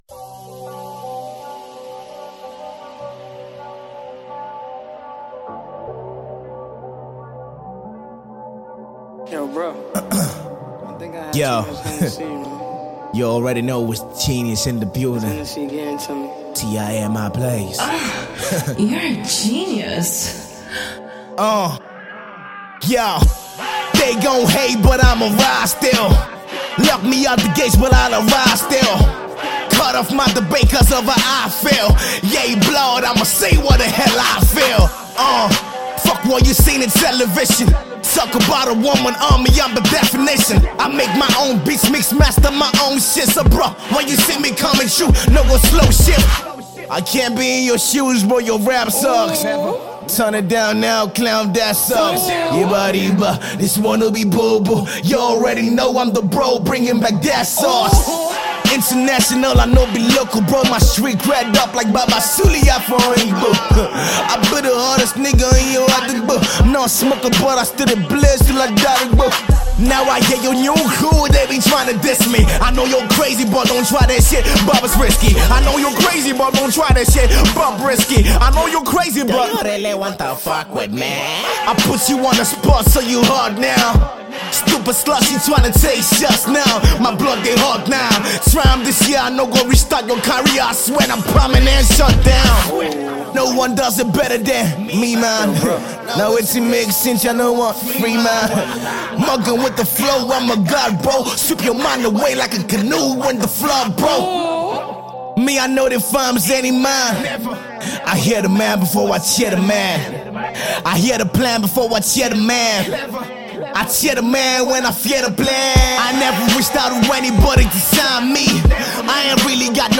hip-hop beats
blazing freestyle